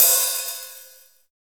POP OHH.wav